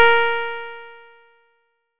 nes_harp_As4.wav